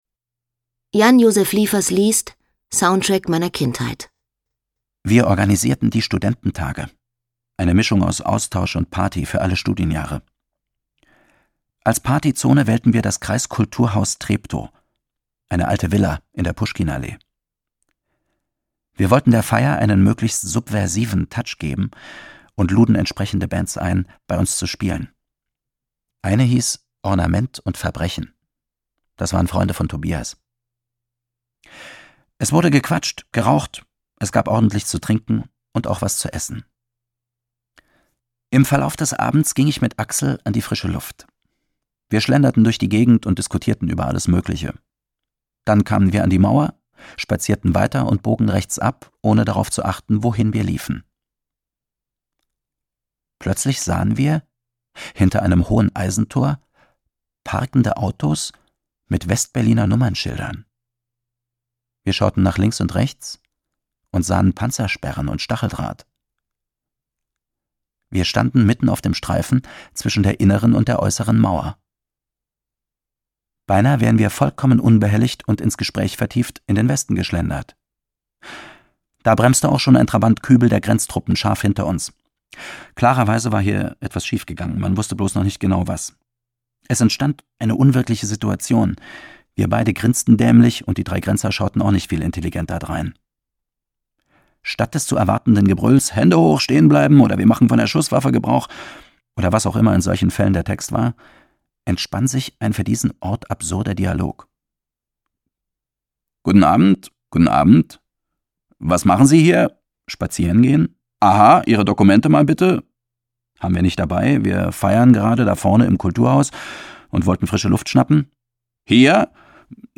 Jan Josef Liefers (Sprecher)
2011 | 5. Auflage, Gekürzte Ausgabe
Ein ganz persönliches Hörbuch über eine Kindheit in der DDR